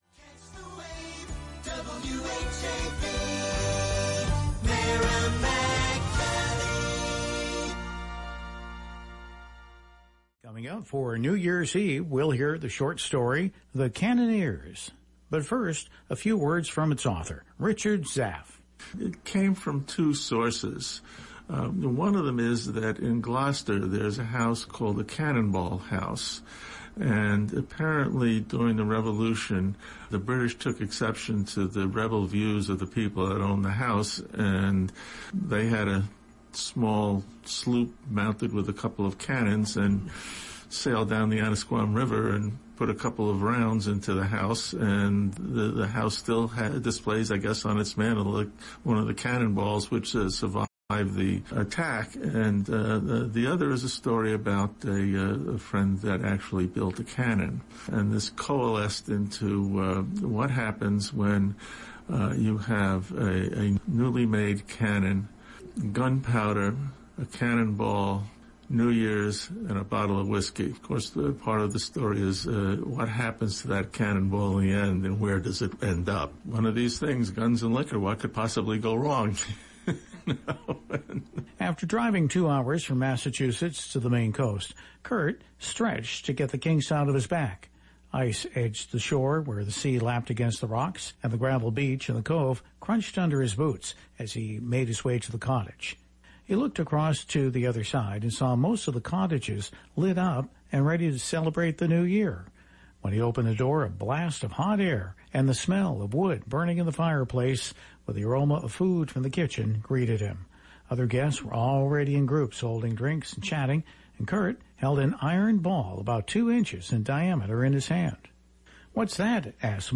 The Cannoneers read on New Year’s Eve on local radio station WHAV 97.9 FM